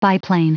Prononciation du mot biplane en anglais (fichier audio)
Prononciation du mot : biplane